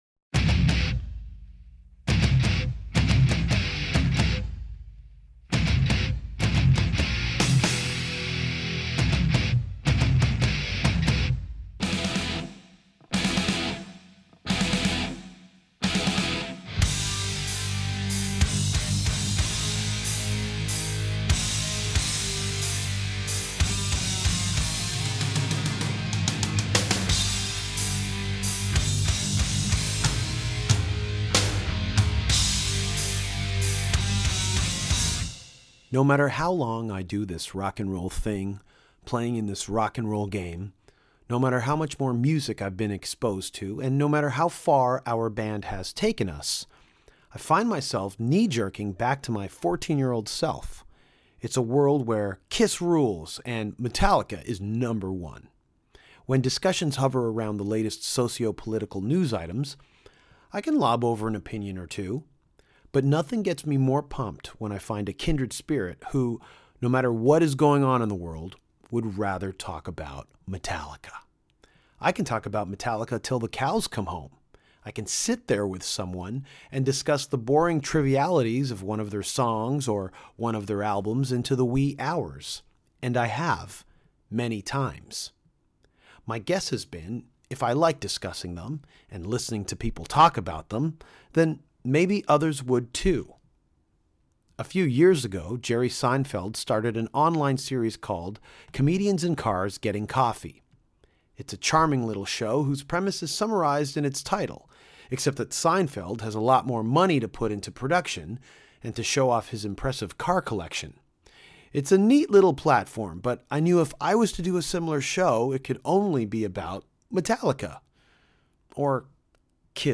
Episode #105: Musicians On Boats Talking About Metallica